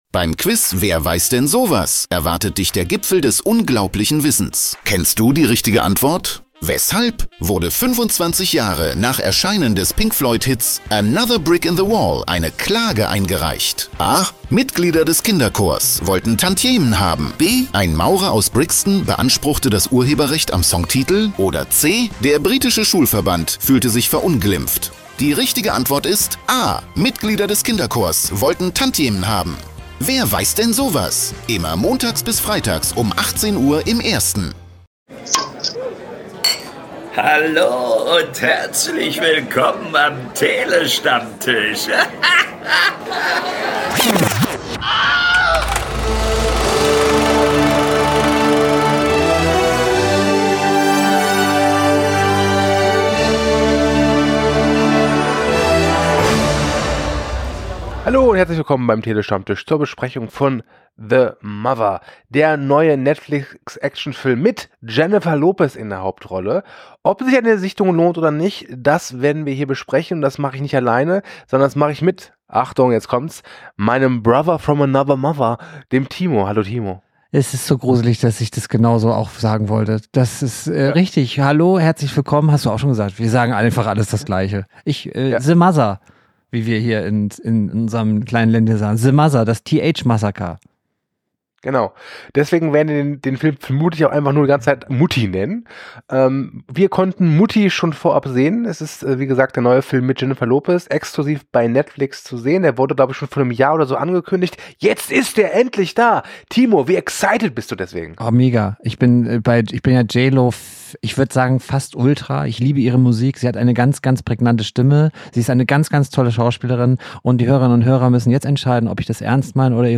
Trailer Wir liefern euch launige und knackige Filmkritiken, Analysen und Talks über Kino- und Streamingfilme und -serien - immer aktuell, informativ und mit der nötigen Prise Humor.
Website | Twitch | PayPal | BuyMeACoffee Großer Dank und Gruß für das Einsprechen unseres Intros geht raus an Engelbert von Nordhausen - besser bekannt als die deutsche Synchronstimme Samuel L. Jackson!